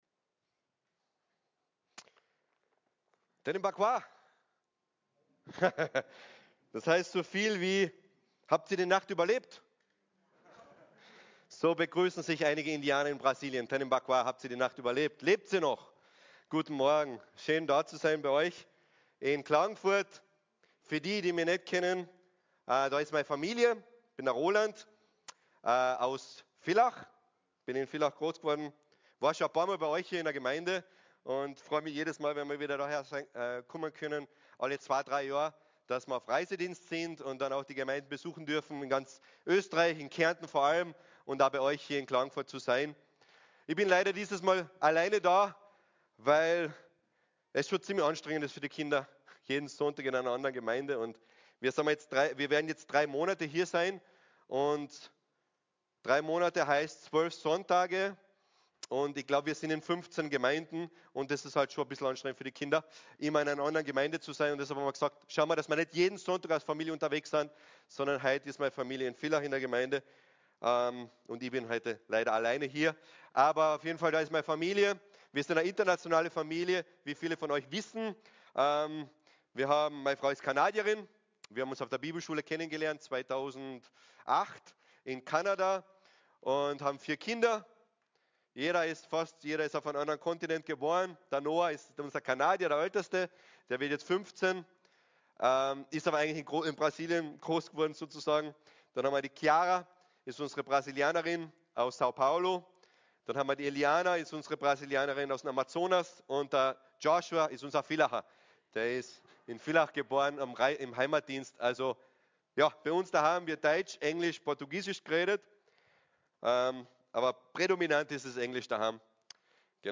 Eine predigt aus der serie "Einzelpredigten 2026."